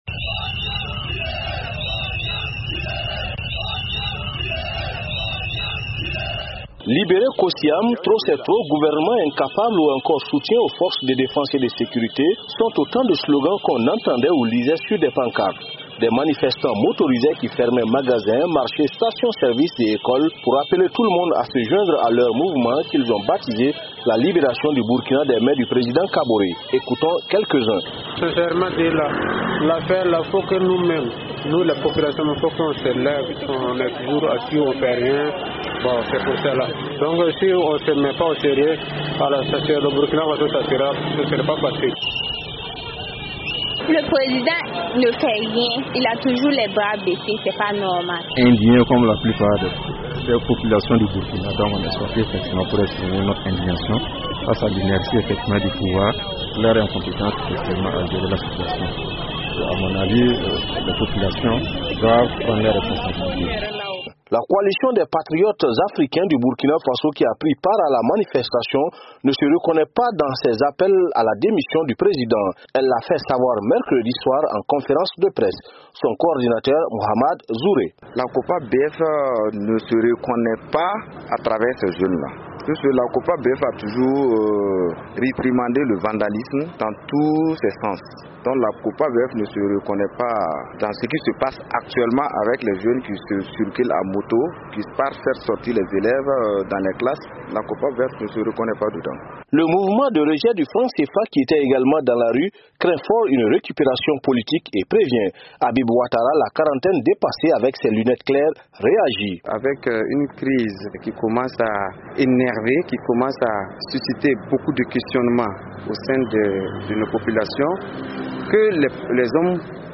Reportage à Bobo Dioulasso